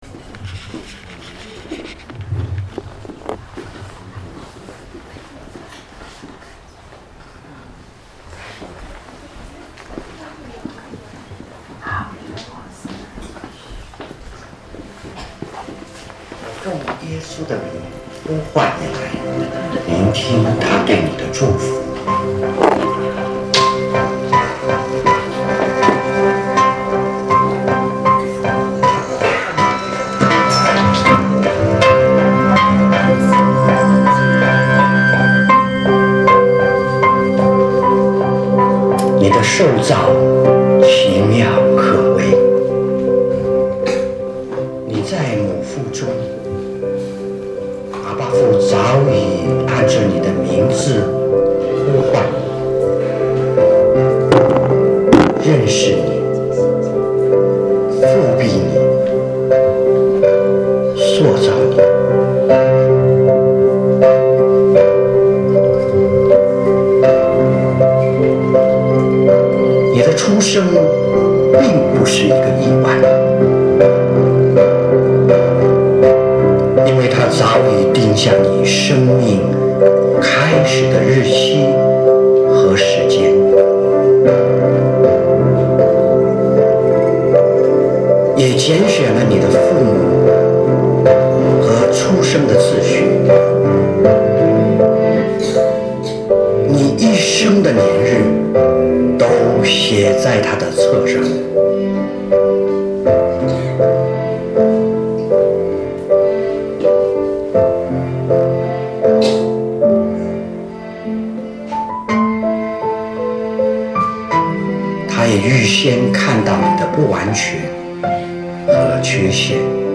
正在播放：--主日恩膏聚会录音（2014-11-09）